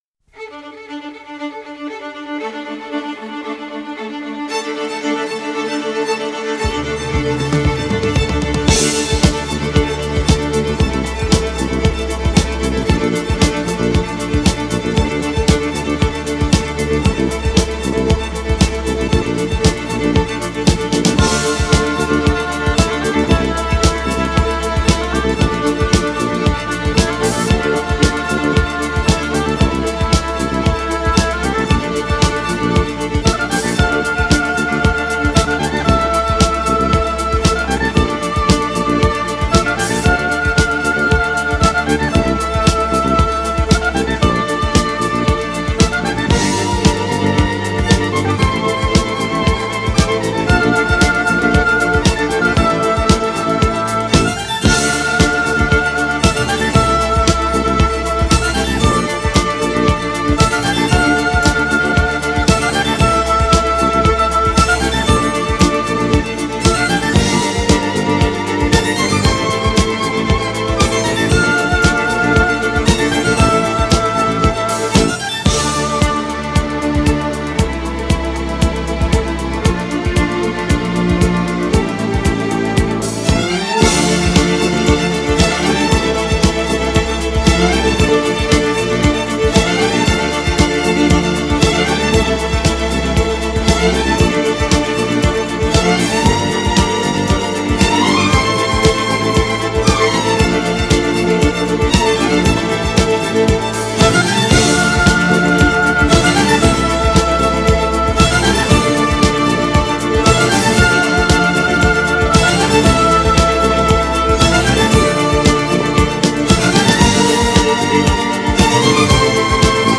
Genre: Classical / Neo Classical